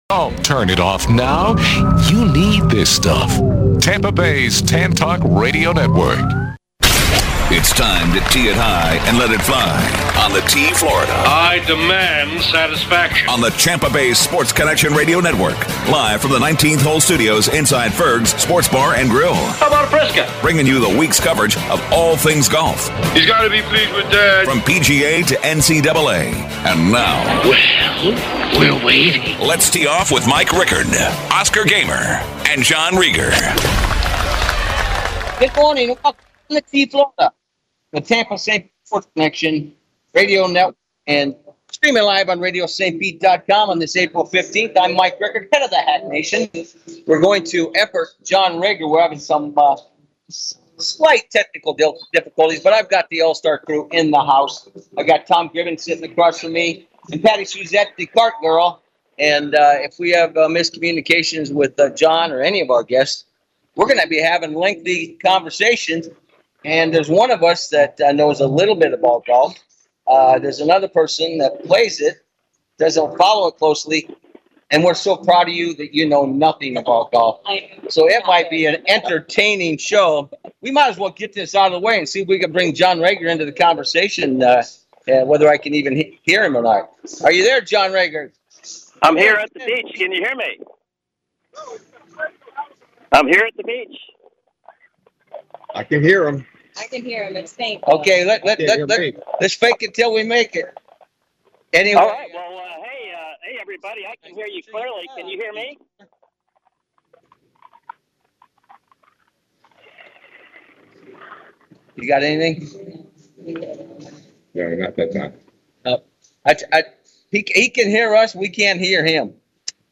Live from Ferg's